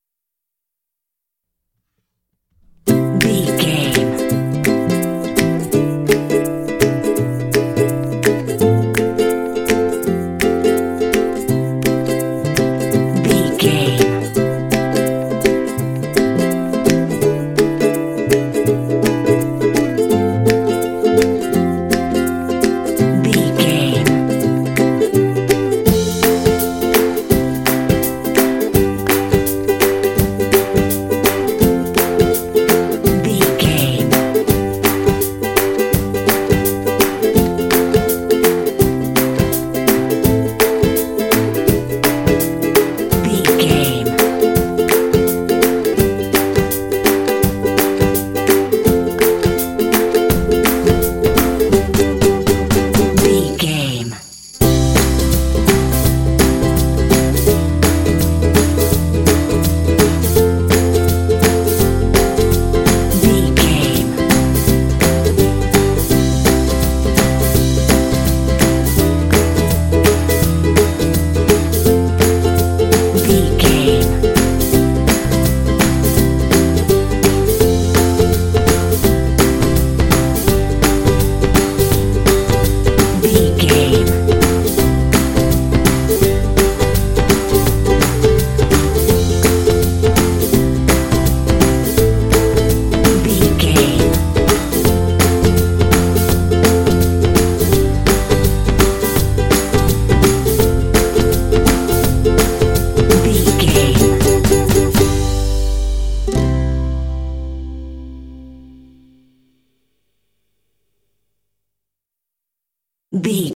Ionian/Major
positive
bright
happy
percussion
bass guitar
acoustic guitar
pop